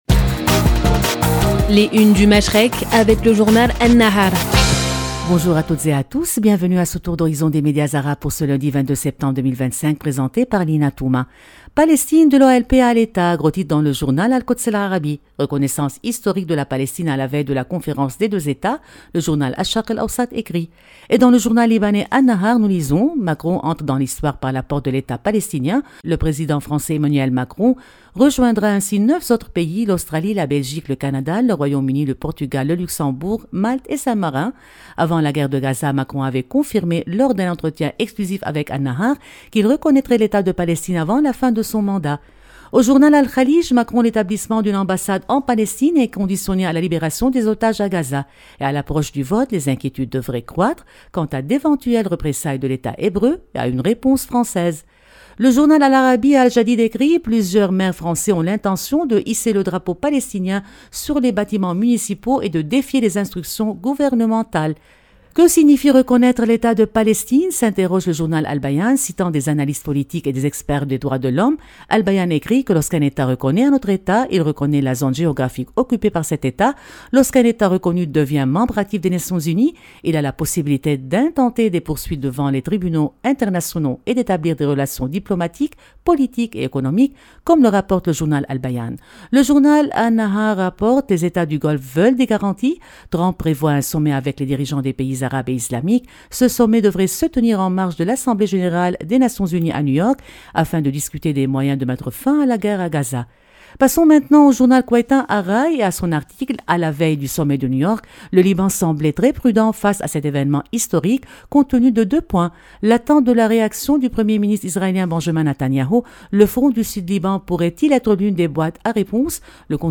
Revue de presse des médias arabes